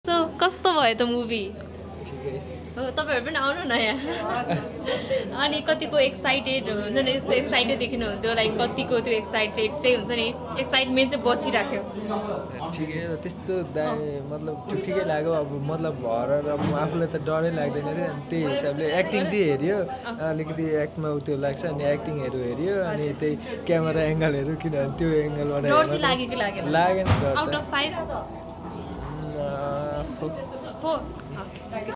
Nepali audience shares their honest reaction to NOISE.